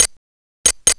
TRIANGLELP-L.wav